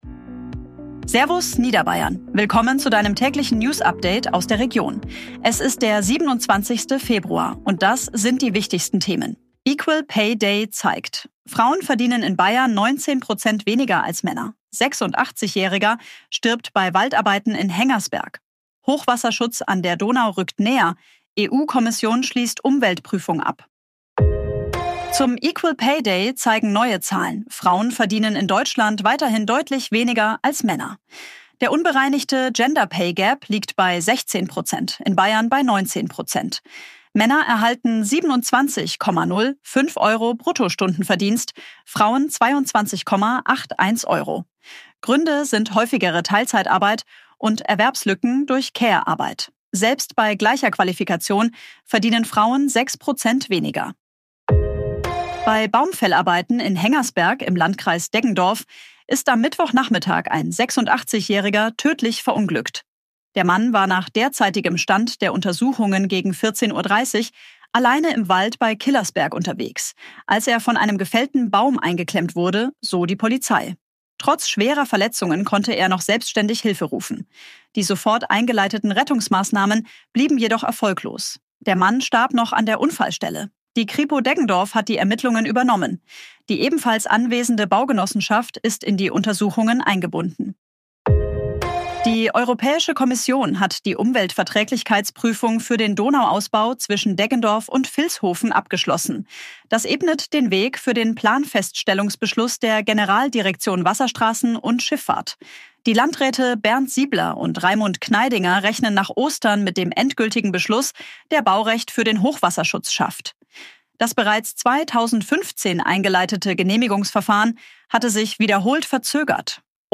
Tägliche Nachrichten aus deiner Region
ausgeliefert und stellt sich dumm Dieses Update wurde mit